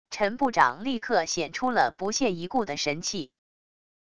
陈部长立刻显出了不屑一顾的神气wav音频生成系统WAV Audio Player